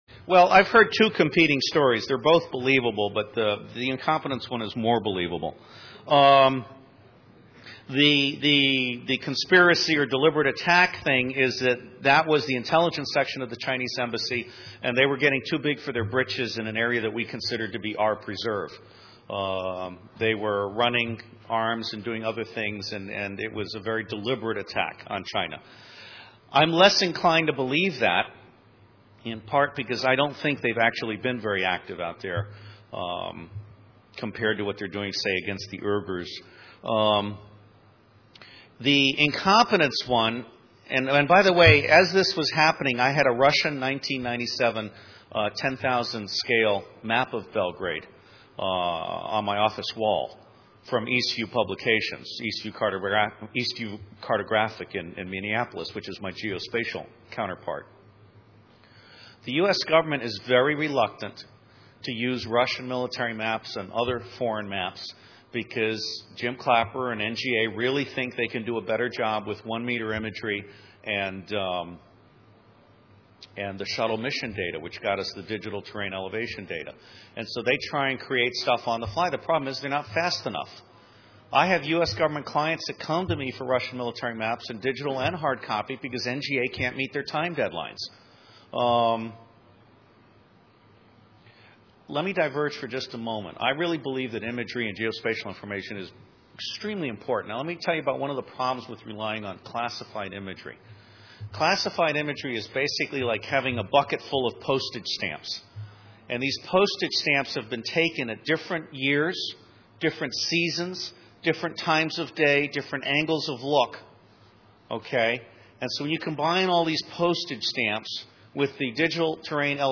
a speech